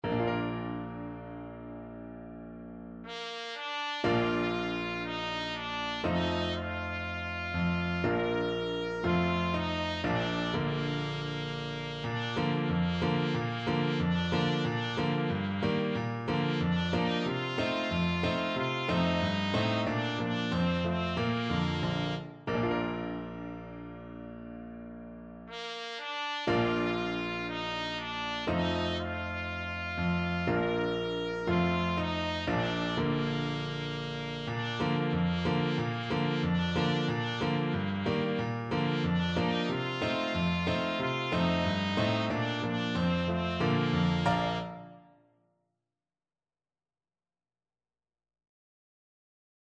Christmas
2/2 (View more 2/2 Music)
Slow =c.60